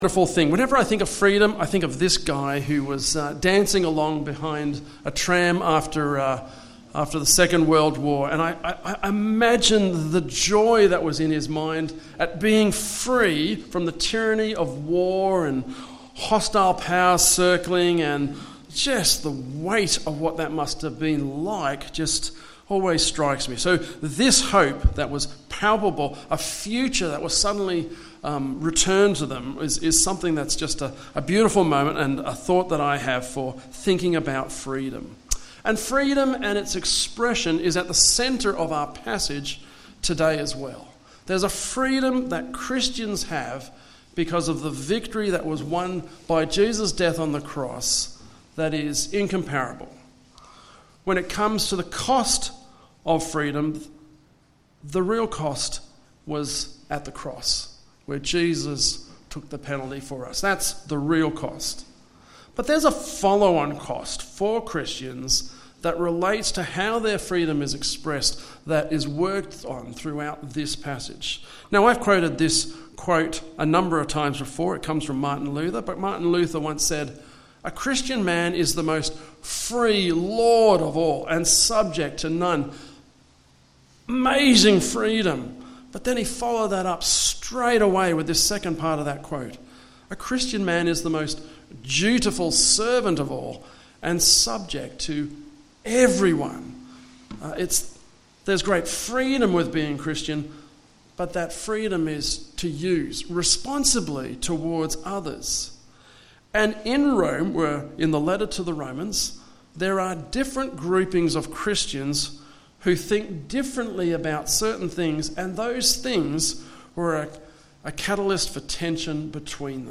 Western Blacktown Presbyterian Church is now Hope at the Hill meeting at Rooty Hill.
Sermon